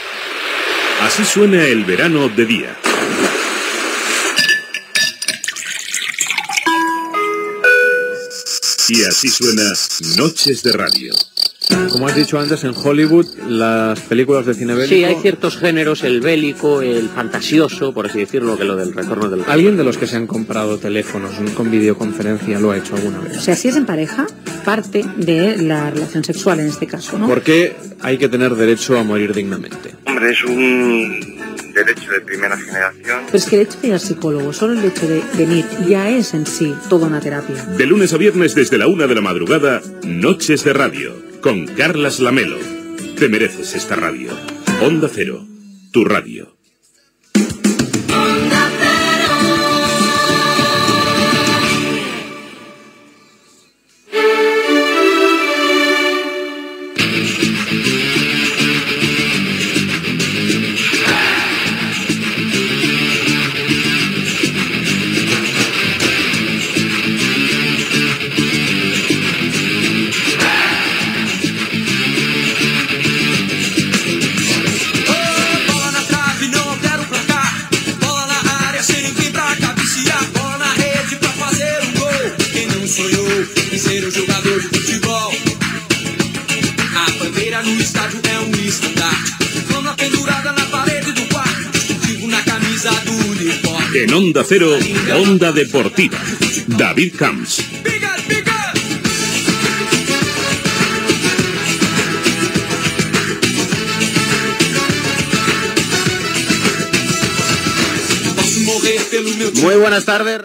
indicatiu de la ràdio, careta del programa Onda Deportiva